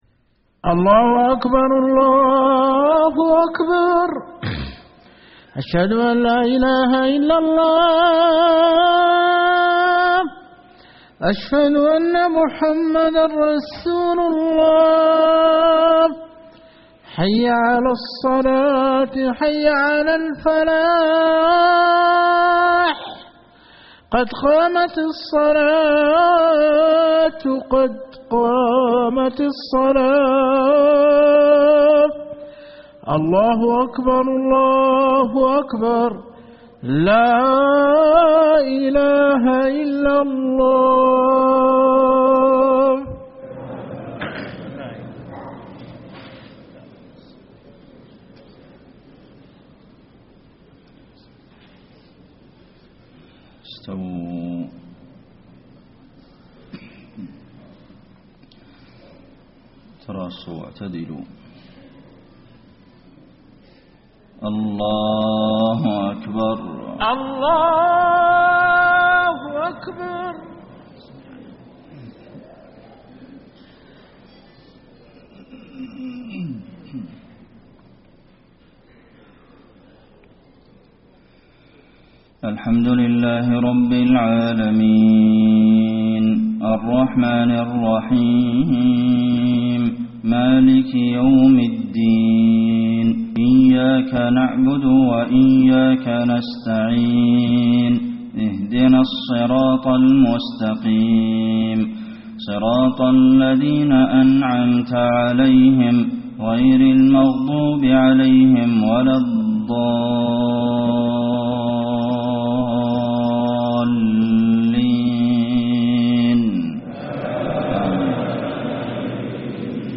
صلاة الفجر 7-8-1434 من سورة الأنفال > 1434 🕌 > الفروض - تلاوات الحرمين